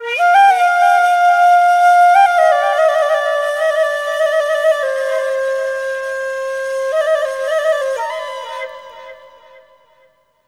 EASTFLUTE1-L.wav